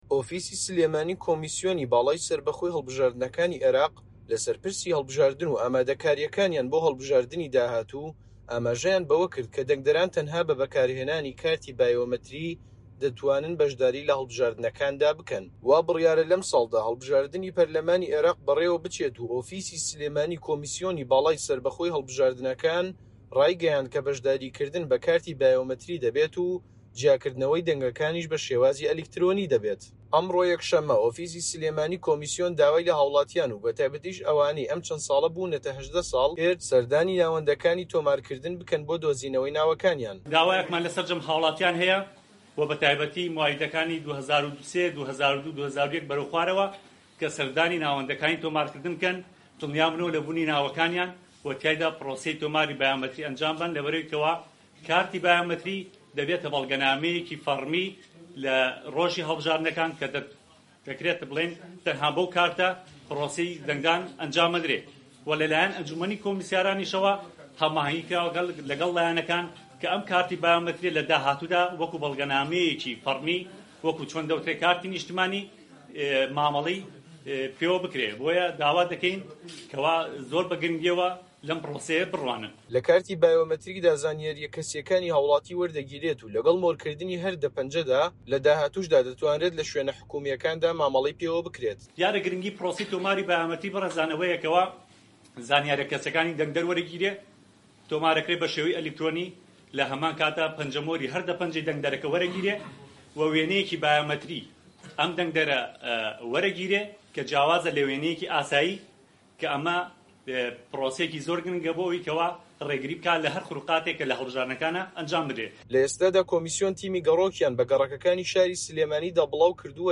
ڕاپۆرتی